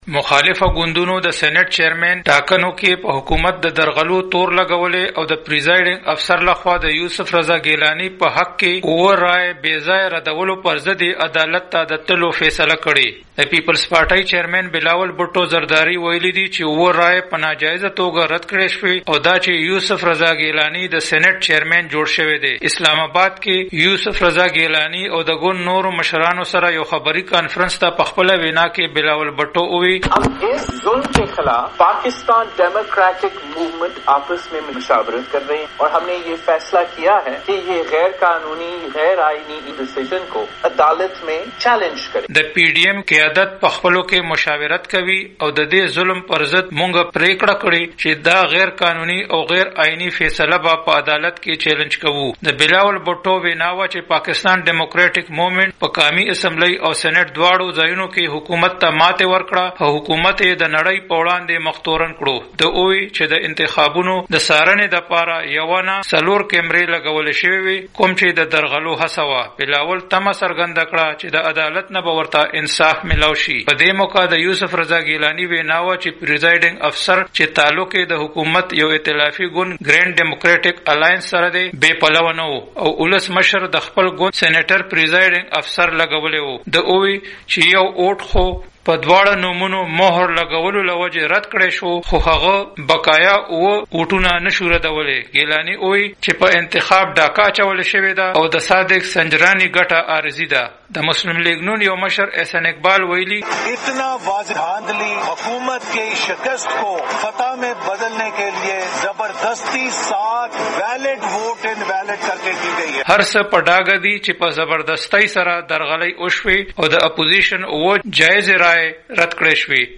رپورټ